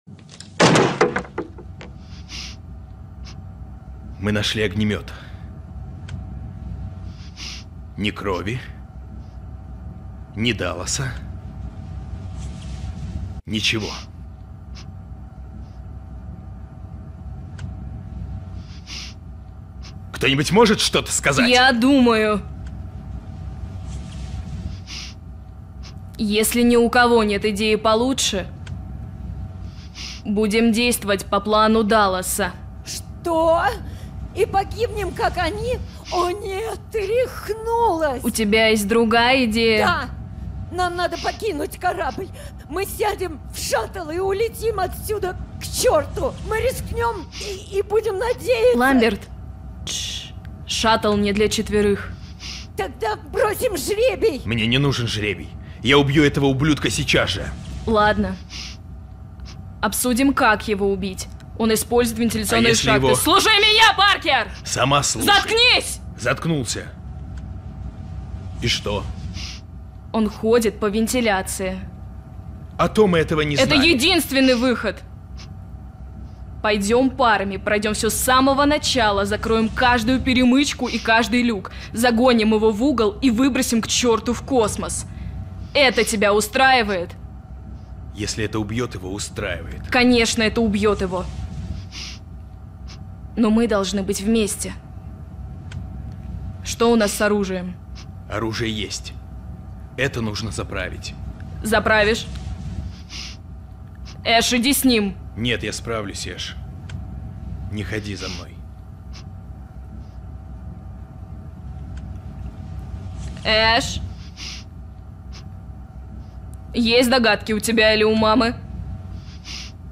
Жен, Другая
Студийный микрофон FiFine AM8